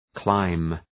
Shkrimi fonetik {klaım}
clime.mp3